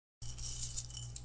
硬币的声音 " 4个硬币
描述：我用我的电容式麦克风录制硬币。
Tag: 现金 硬币